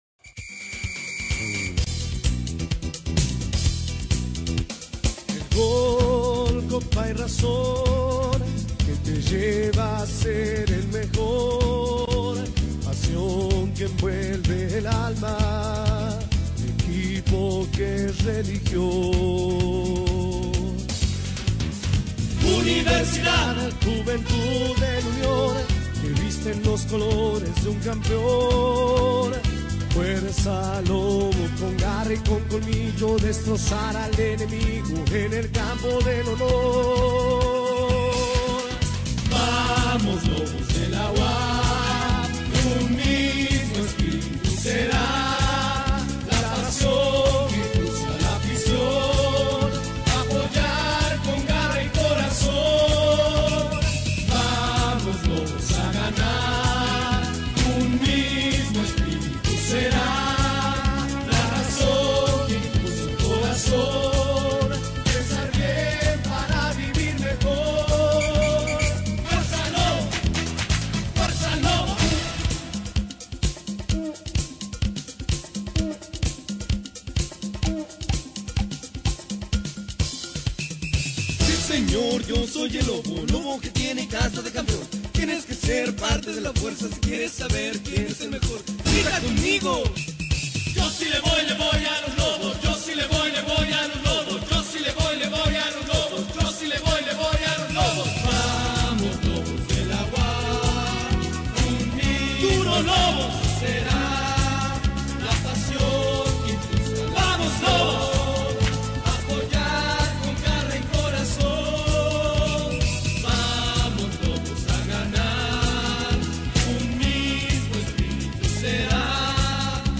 Himnos